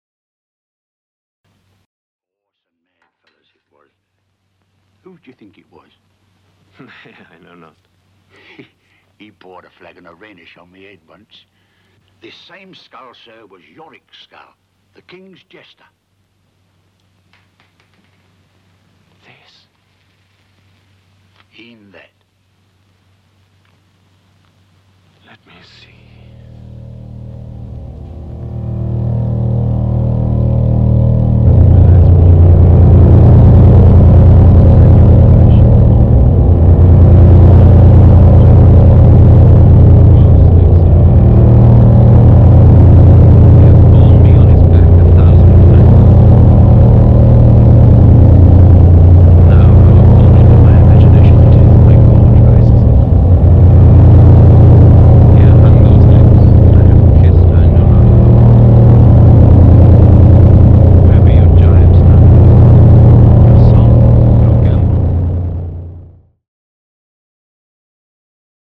First full lenght solo studio album since 2001 !
the greatest Japanese noise artists of all times..